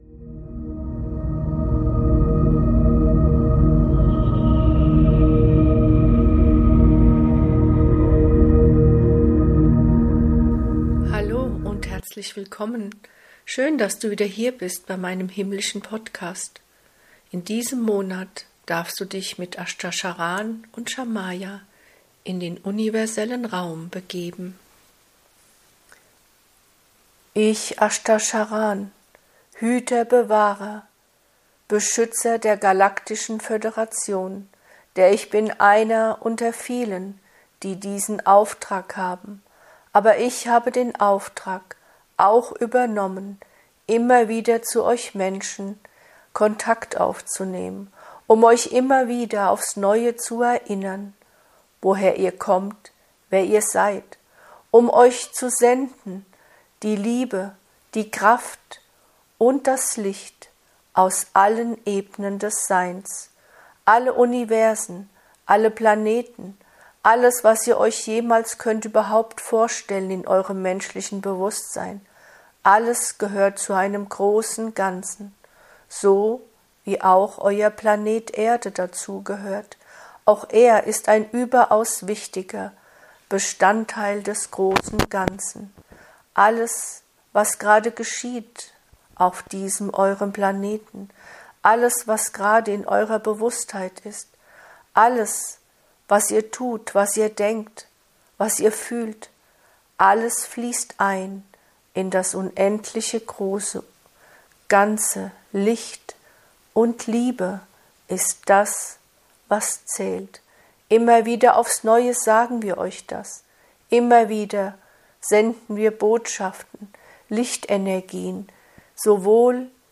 In meinem Podcast findest du durch mich direkt gechannelte Lichtbotschaften.